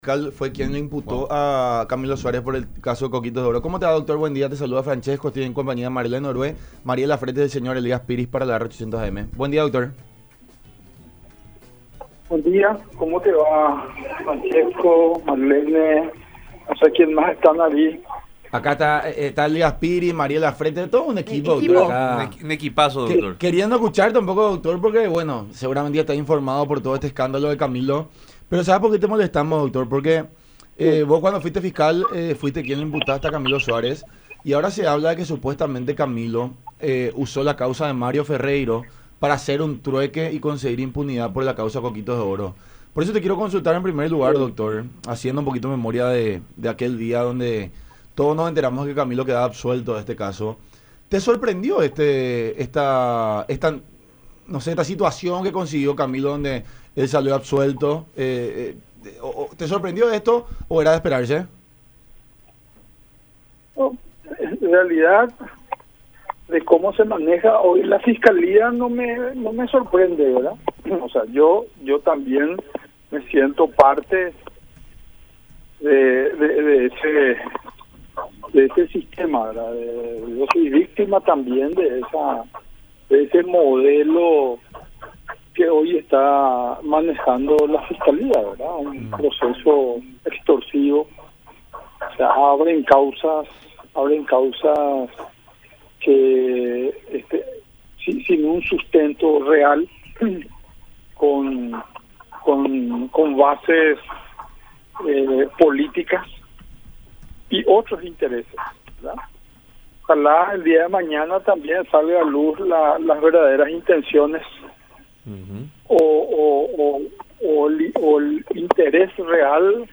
explicó Giuzzio en diálogo con La Unión Hace La Fuerza por Unión TV y radio La Unión, acerca del caso “coquitos de oro”, el cual reveló que se habrían negociado cerca de G. 4.000 millones de perjuicio a la institución.